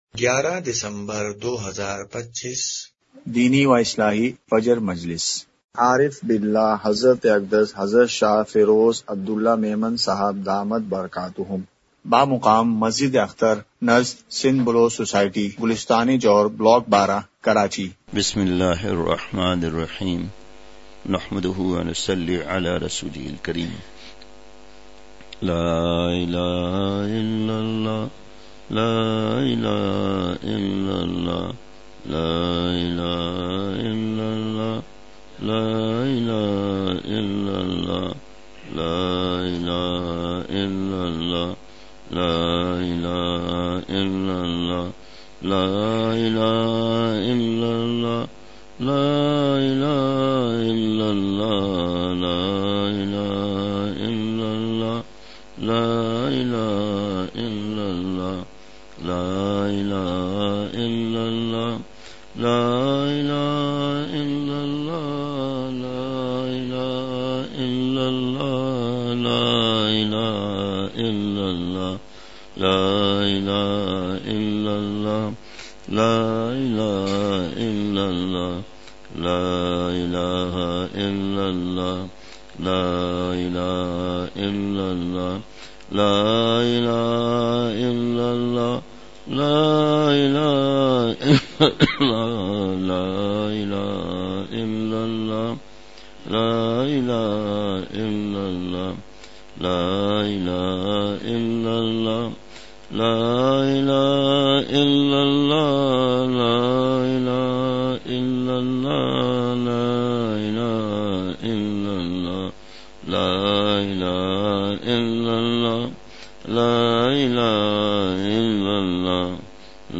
اصلاحی مجلس کی جھلکیاں مقام:مسجد اختر نزد سندھ بلوچ سوسائٹی گلستانِ جوہر کراچی